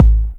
Kick Turntable B 2.wav